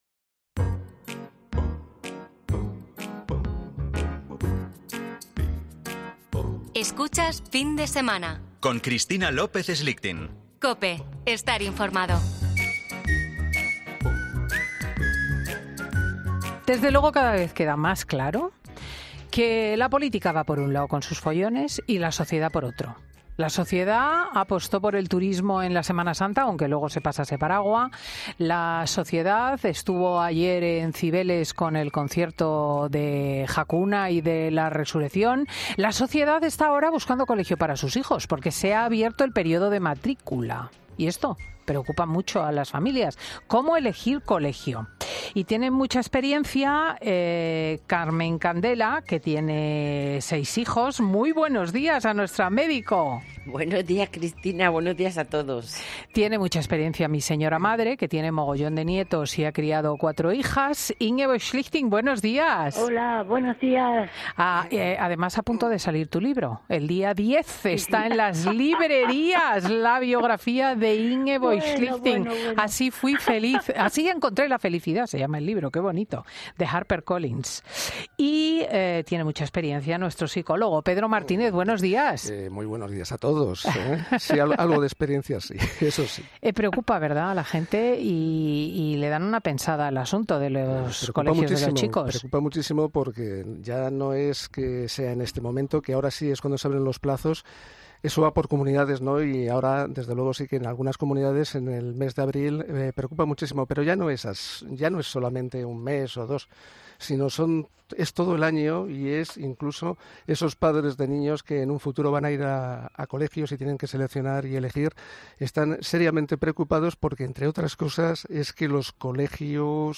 es un magazine que se emite en COPE , los sábados y domingos, de 10.00 a 14.00 horas.